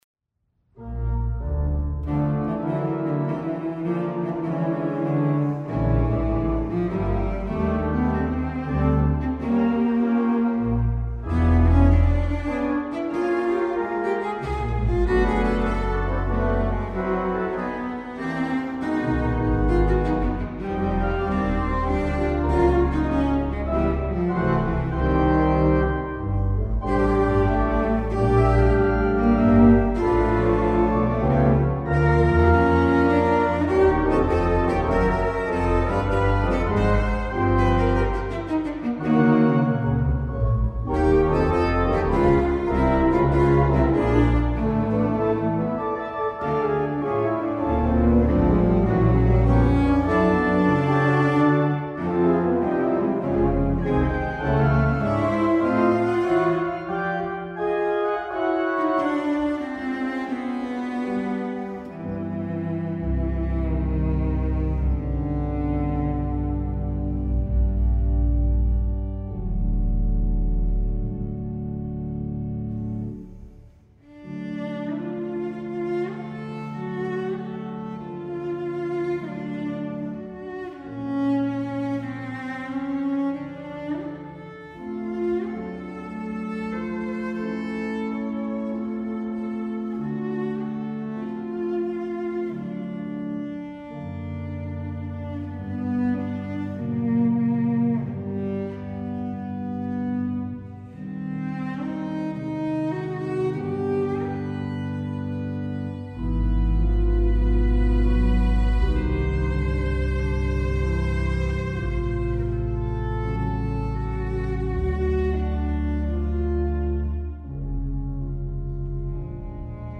all live, and all unedited.
Cello and Organ